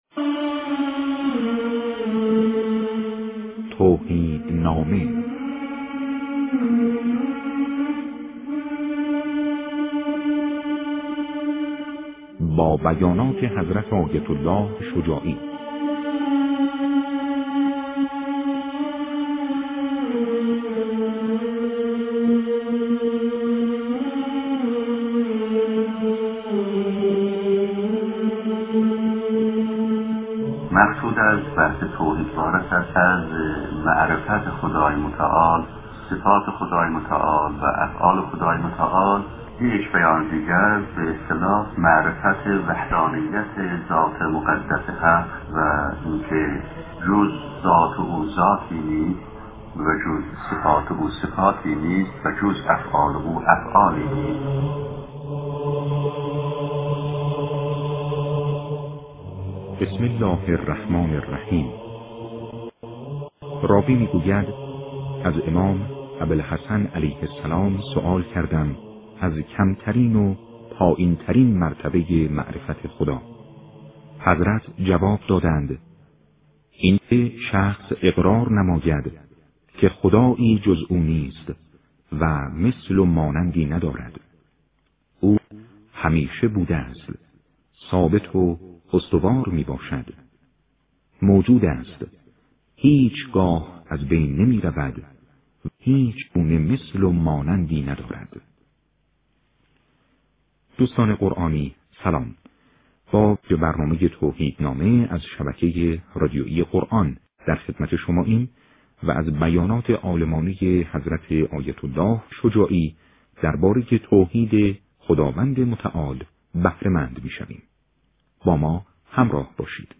سخنرانی در مورد توحید